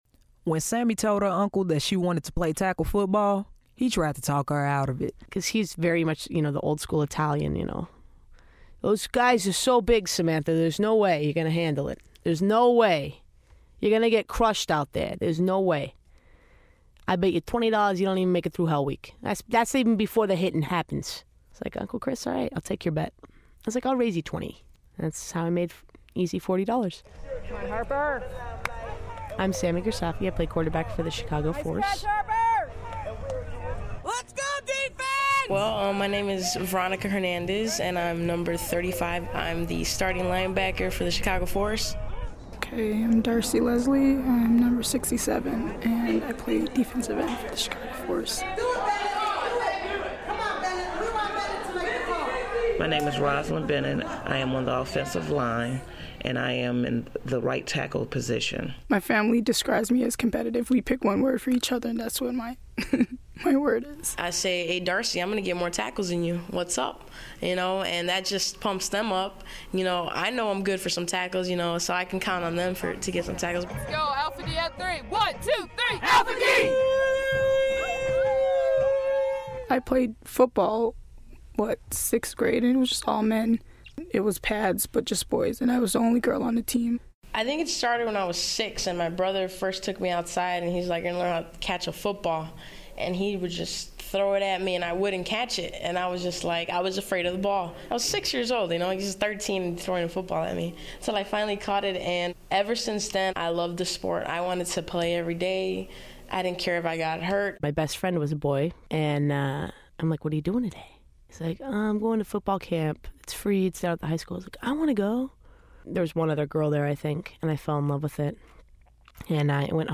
In this piece, four members of the team describe what it’s like to be a woman in a man’s game.